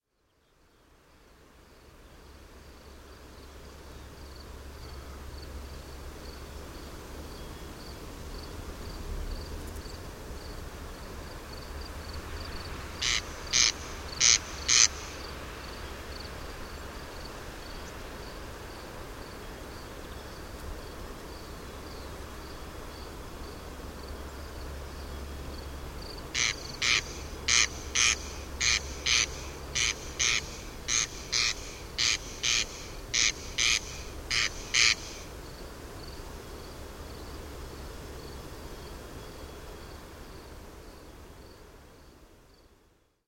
Grillons et râle des genêts au col Bayard